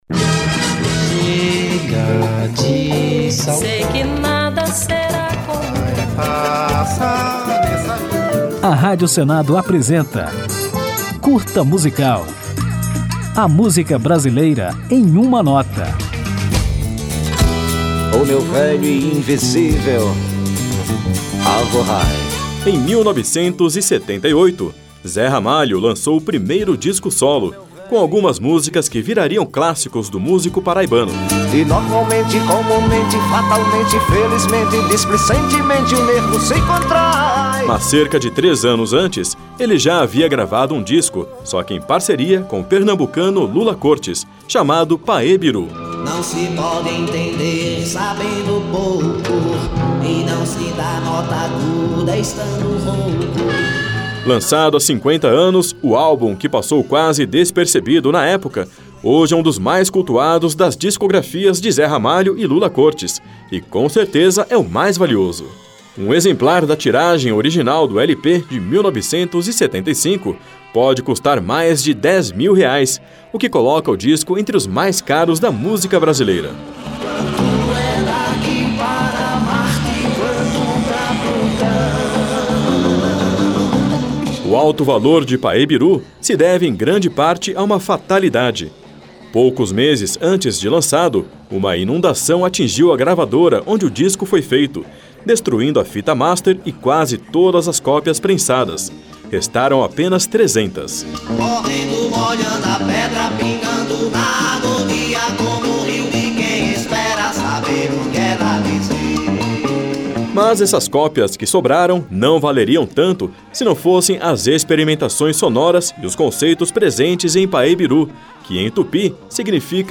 Neste Curta Musical você vai conhecer a história do álbum Paêbirú, um dos mais raros da discografia brasileira, lançado em 1975 por de Zé Ramalho e Lula Côrtes. Quase todo instrumental e cheio de influências regionais e psicodélicas, o disco foi inspirado nas lendas em torno das escrituras rupestres da Pedra do Ingá, uma relíquia arqueológica da Paraíba.
Ao final, ouviremos Pedra Templo Animal, uma das faixas do lendário Paêbirú, de Lula Côrtes e Zé Ramalho.